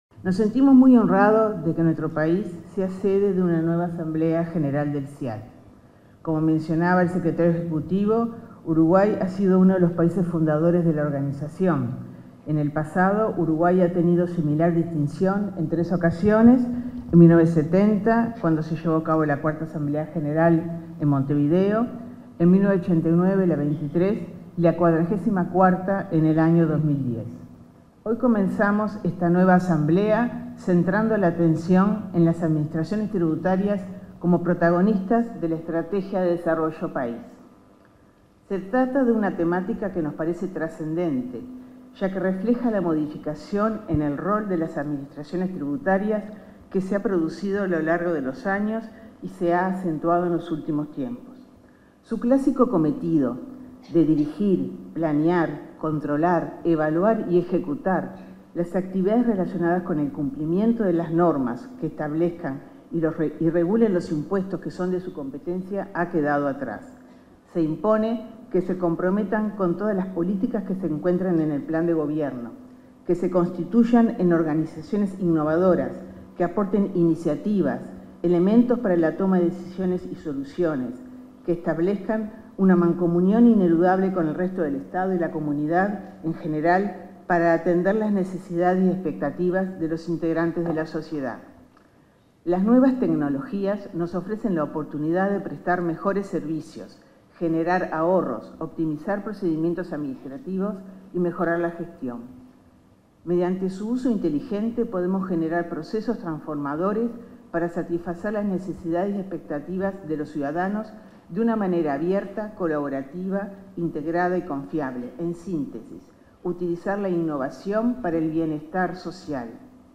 Autoridades en 57.ª Asamblea General del Centro Interamericano de Administraciones Tributarias 23/05/2023 Compartir Facebook X Copiar enlace WhatsApp LinkedIn En el marco de la celebración de la 57.ª Asamblea General del Centro Interamericano de Administraciones Tributarias, este 23 de mayo, se expresaron el subsecretario de Economía y Finanzas, Alejandro Irastorza, y la titular de la Dirección General Impositiva (DGI), Margarita Faral.